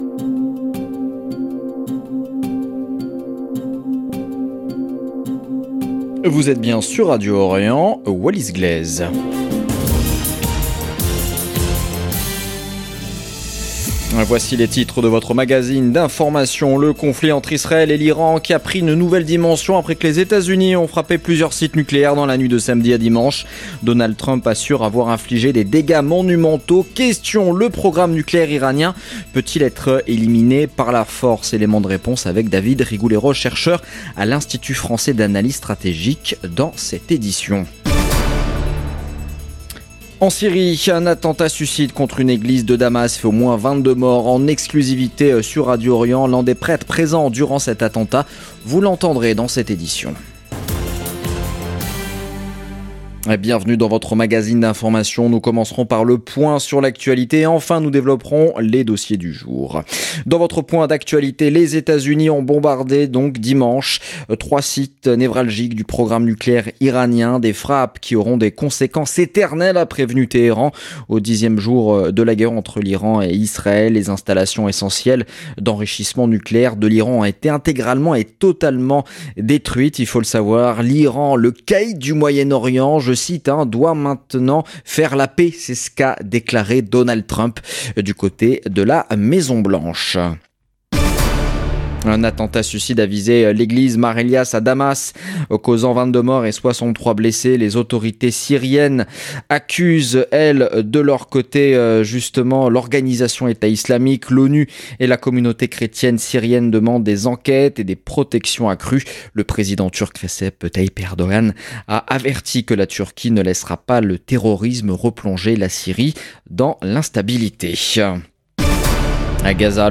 Magazine de l'information de 17H00 du 23 juin 2025
Syrie : attentat suicide meurtrier à Damas, 22 morts dans une église Témoignage exclusif d’un prêtre présent lors de l’attaque. Réactions internationales et appels à la protection des chrétiens en Syrie.